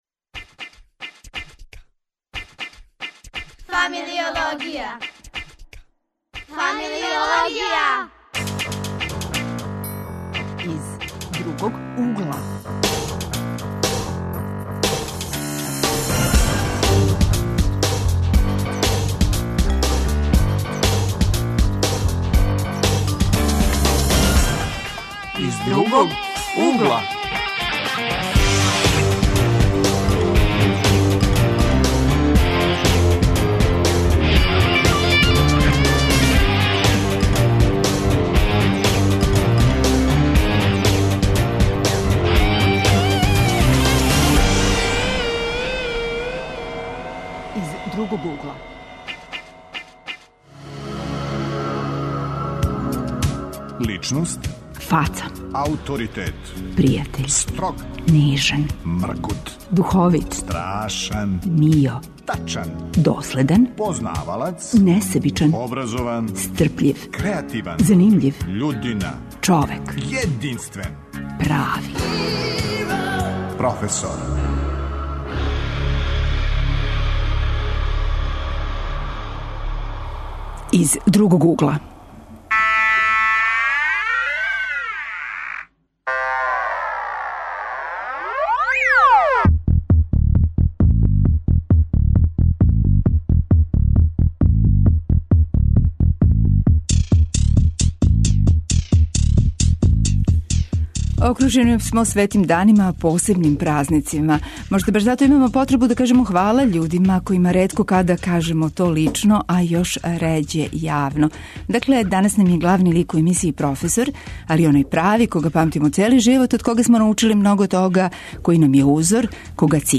Гости су нам матуранти и студенти, путем снимка чућемо професоре по њиховом избору.